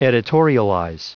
Prononciation du mot editorialize en anglais (fichier audio)